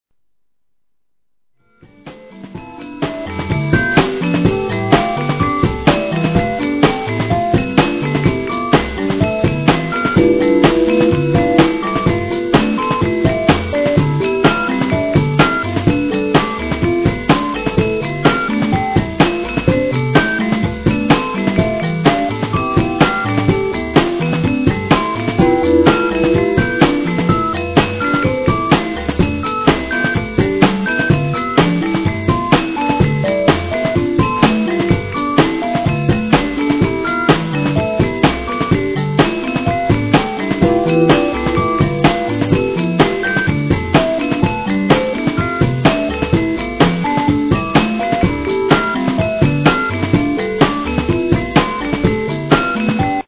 UK BREAK BEATS!!!